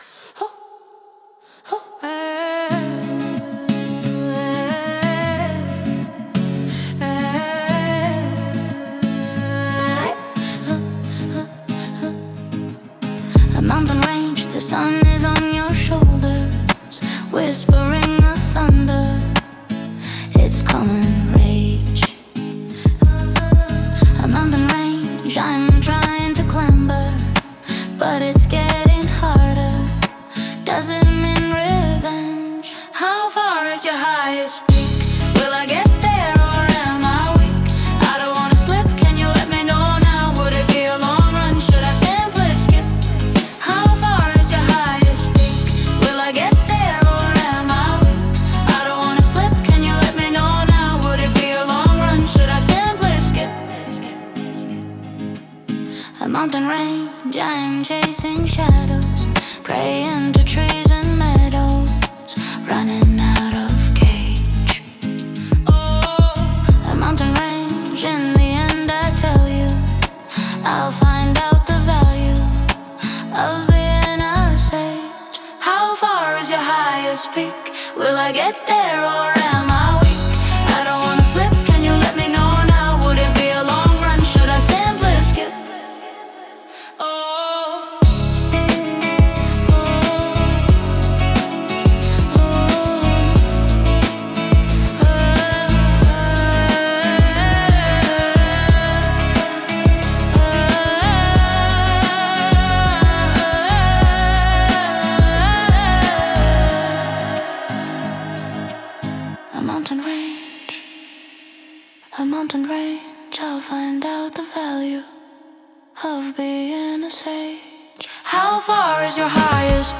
Пример 6: Старый добрый олд-скул
олд-скул рэп, средний темп, прямой качовый бит, жёсткий кик, чёткий снейр на 2 и 4, минимум хэтов, винтажное звучание